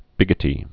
(bĭgĭ-tē)